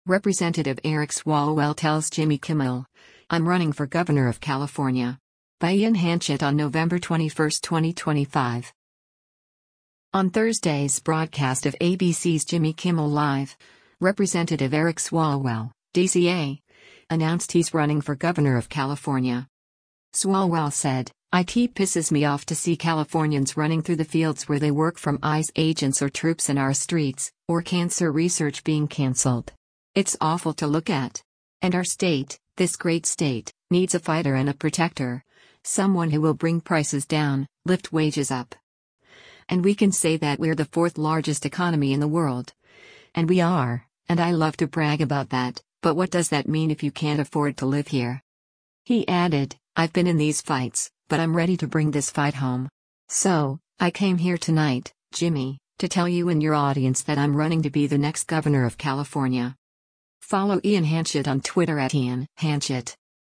On Thursday’s broadcast of ABC’s “Jimmy Kimmel Live,” Rep. Eric Swalwell (D-CA) announced he’s running for governor of California.